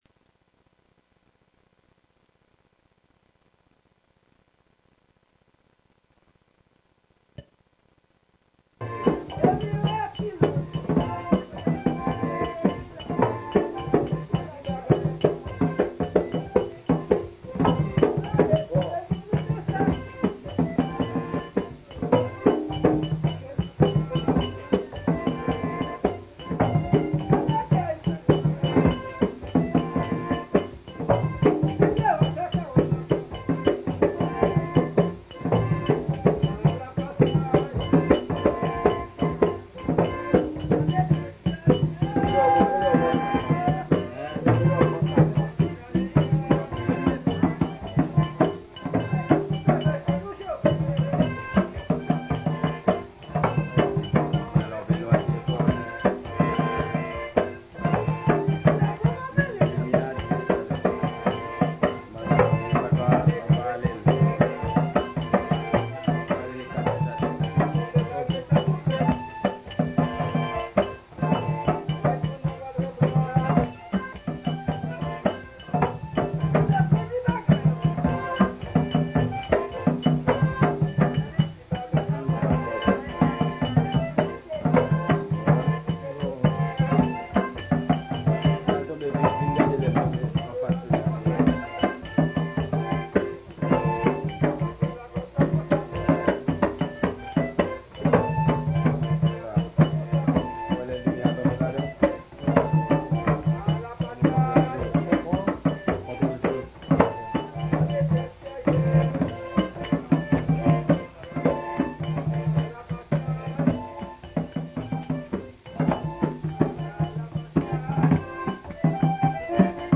FLOKLORE HAITIEN